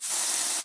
气溶胶喷雾声 " Aerosol9
描述：记录来自小型（125ml）气雾剂除臭剂的9种不同的喷雾声。非常干净的录音。录制了第五代iPod touch。
标签： 水枪 喷雾 发胶 气雾剂 喷雾上 喷雾 压力 空气 芳香剂 除臭剂 空气清新剂 爆裂 油漆 喷出
声道立体声